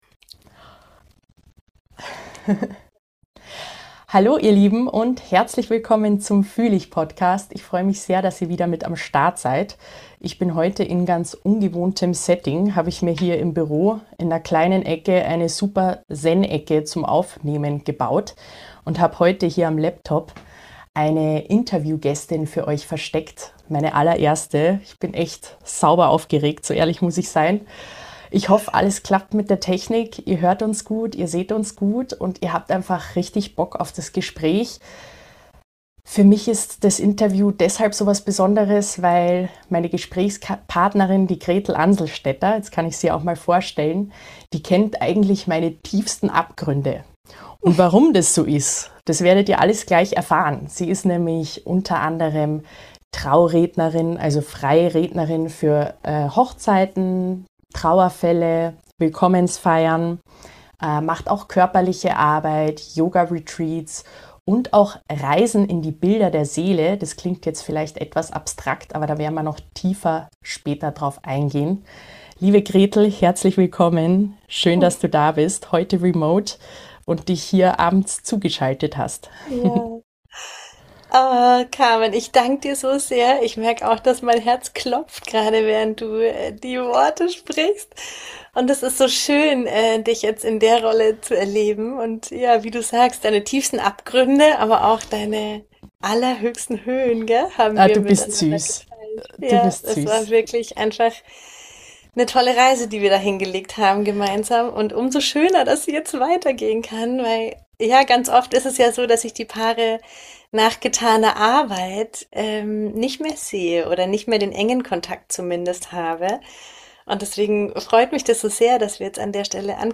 In dieser Folge nehme ich euch mit auf meine aufregende Reise ins erste Podcast-Interview